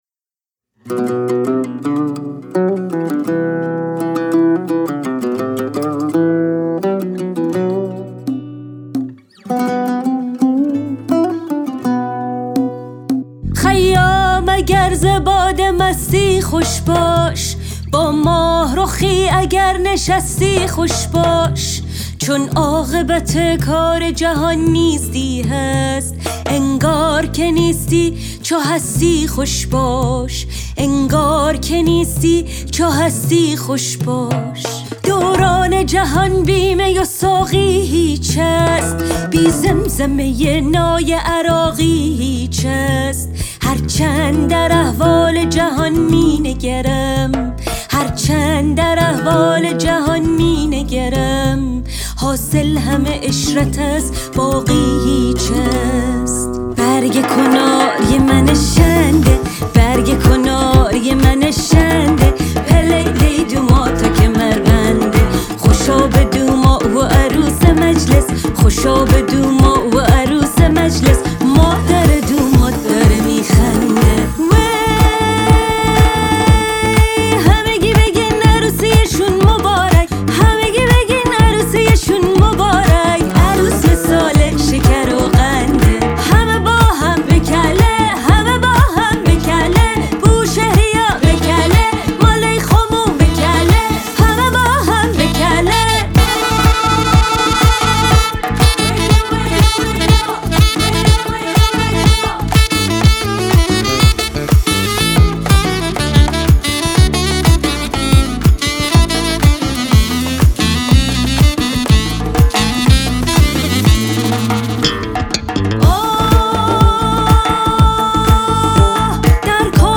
تلفیقی جذاب و پرشور از موسیقی فولکلور جنوب ایران (بوشهر)
با گویش شیرین بوشهری و ترانه‌های محلی